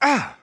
1 channel
ow.wav